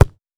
Ball Kick Powerful.wav